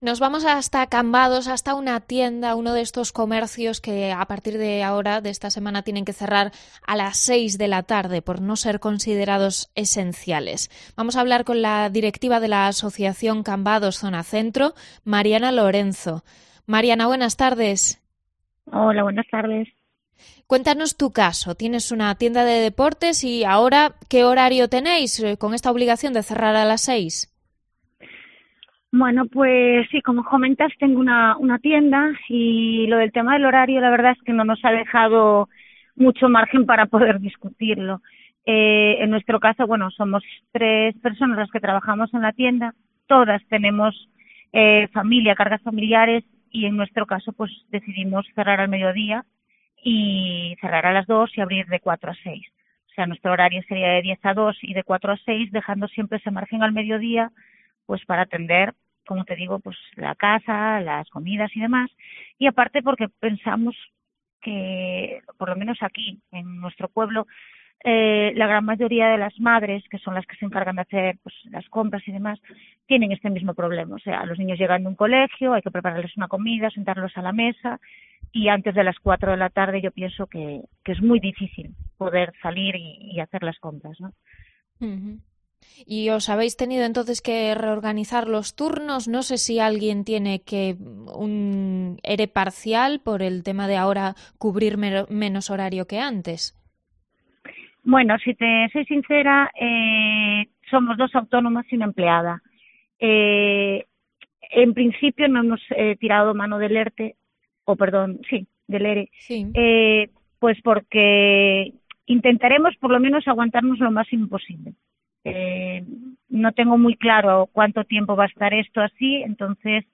Entrevista a la directiva de la asociación Cambados Zona Centro